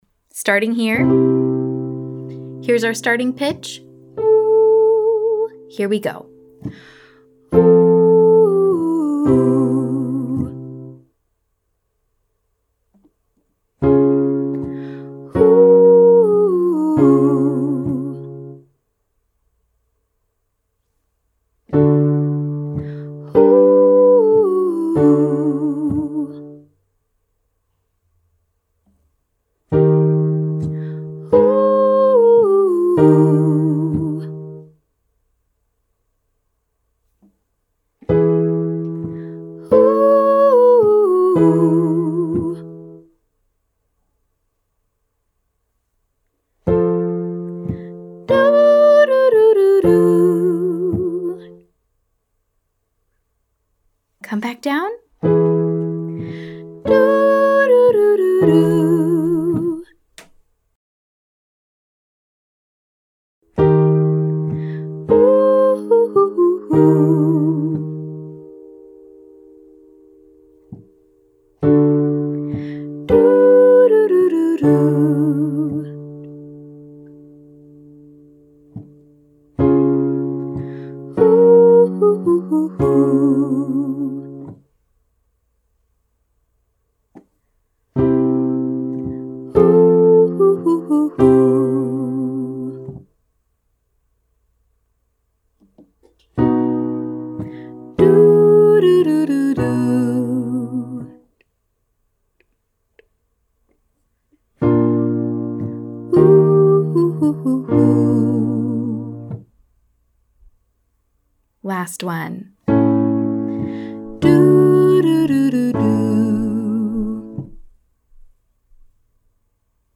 Our first pattern starts on scale degree 6 and descends: [sing 65321].
Our exercise will take this pattern slowly, then as fast as you like.
Exercise 1: 65321 (slow), 65321 (riff)